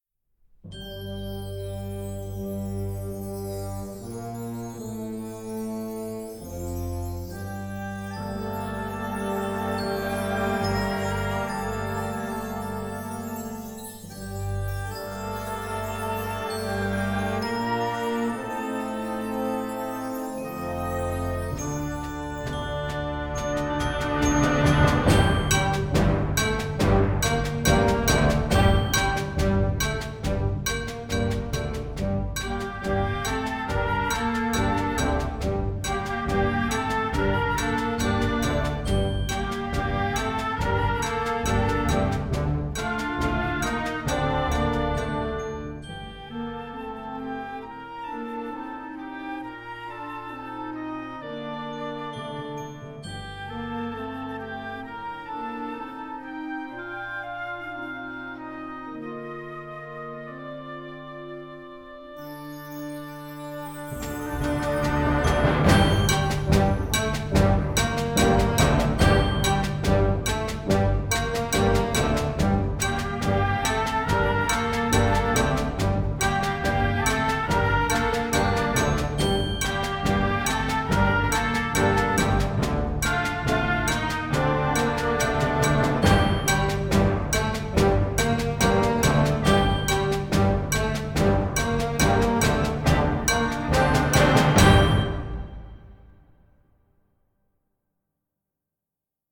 Gattung: Konzertwerk für flexibles Jugendblasorchester
Besetzung: Blasorchester